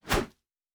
pgs/Assets/Audio/Fantasy Interface Sounds/Whoosh 02.wav at master
Whoosh 02.wav